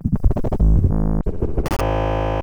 Glitch FX 16.wav